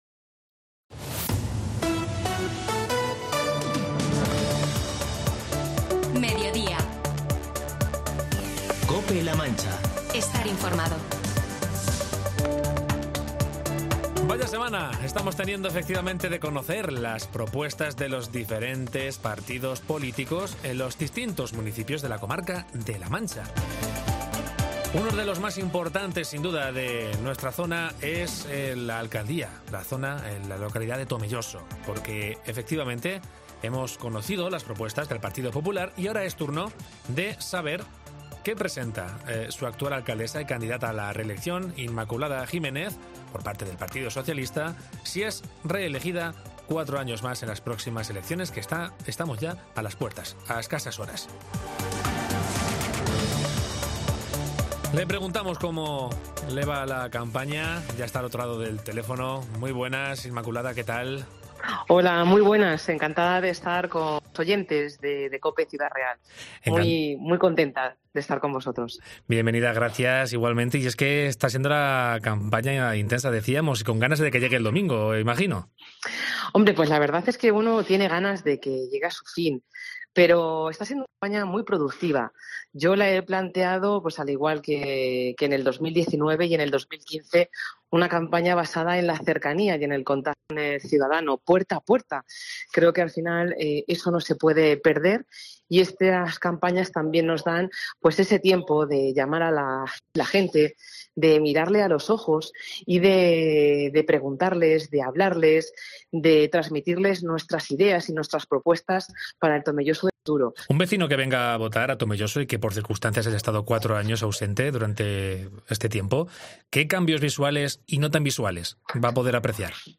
Entrevista a Inmaculada Jiménez, alcaldesa y candidata del PSOE al ayuntamiento de Tomelloso